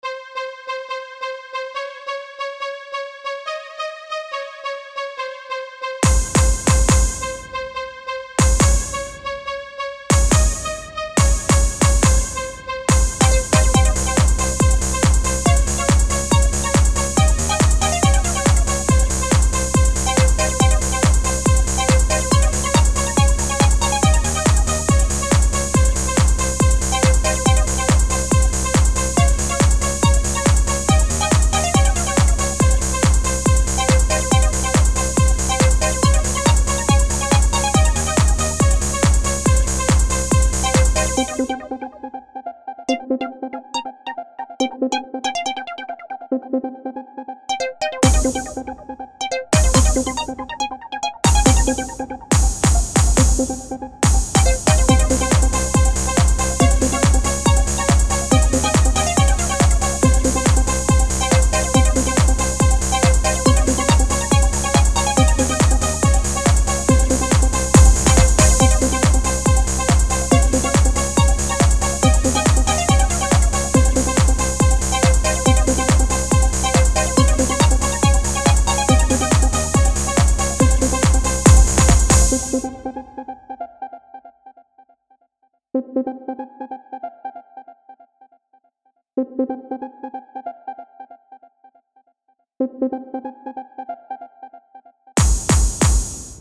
A high-quality club mix of a latin song
tuba